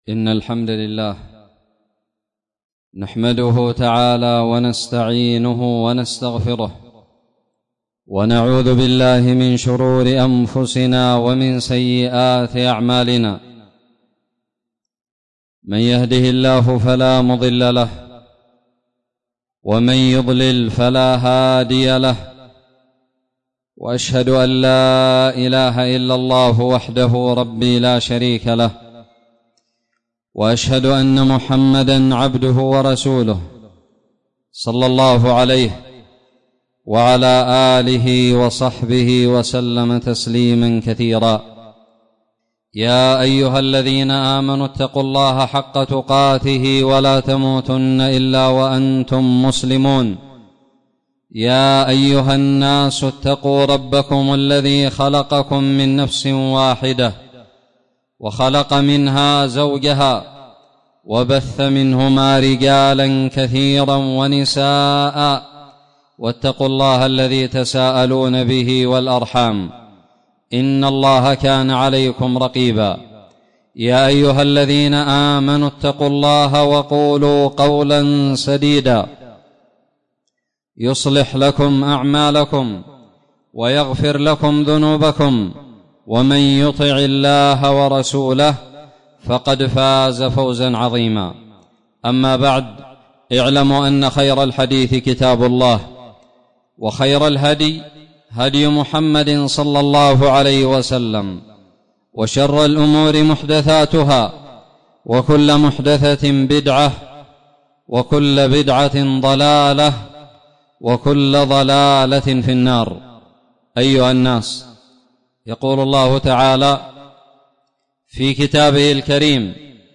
خطب الجمعة
ألقيت بدار الحديث السلفية للعلوم الشرعية بالضالع في 3 ذي الحجة 1441هــ